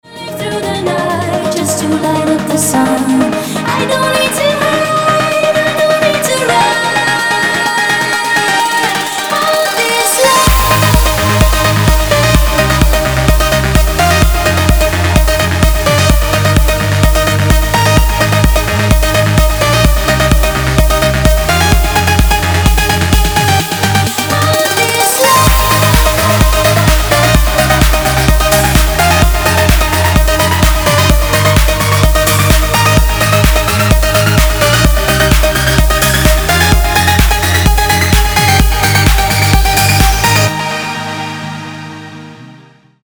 Помогите распознать тембр синтезатора (Прогрессив Хаус 2016)
Прошу помощи экспертов - как приготовить такой бас?